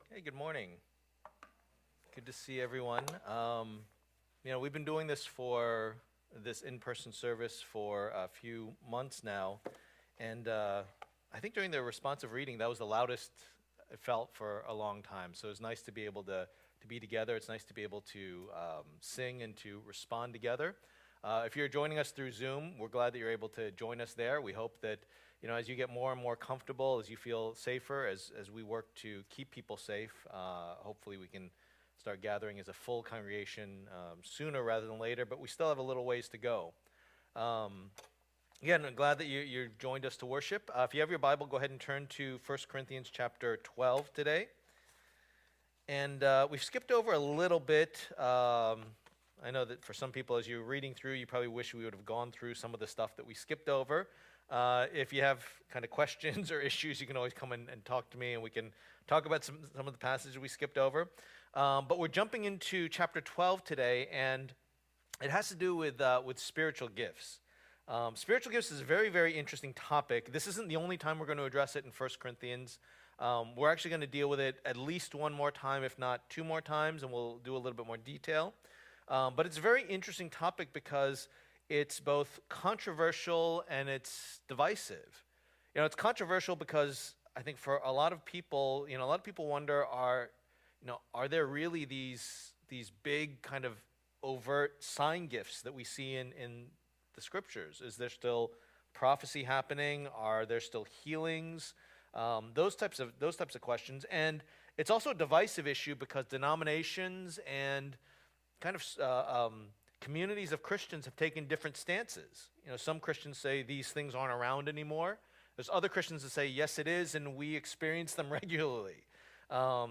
Passage: 1 Corinthians 12:1-11 Service Type: Lord's Day